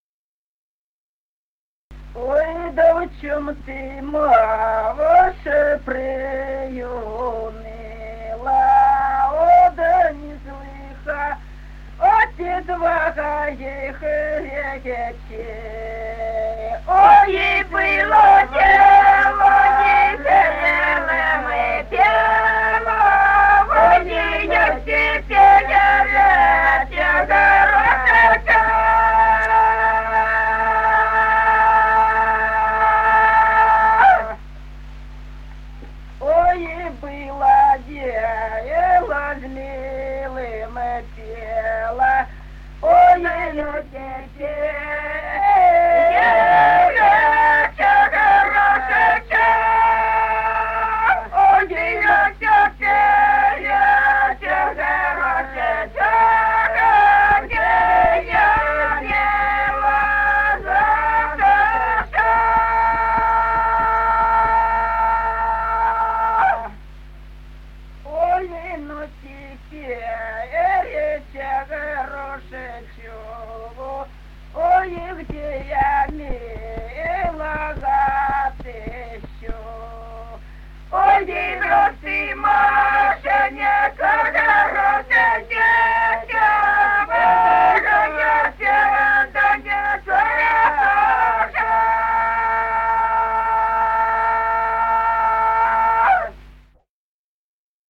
Песни села Остроглядово Ой, да в чём ты, Маша, приуныла.
Песни села Остроглядово в записях 1950-х годов